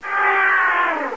00217_Sound_trumpet.mp3